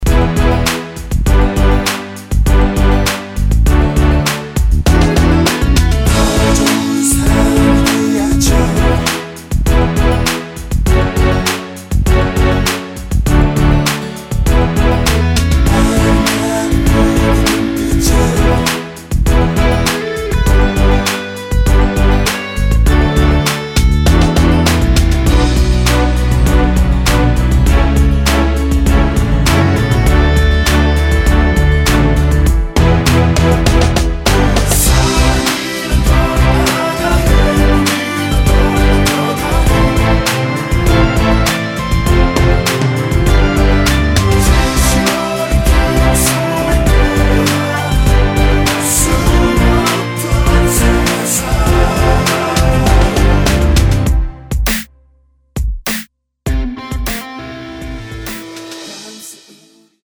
원키에서(-2)내린 코러스 포함된 MR입니다.(미리듣기 참조)
앞부분30초, 뒷부분30초씩 편집해서 올려 드리고 있습니다.
중간에 음이 끈어지고 다시 나오는 이유는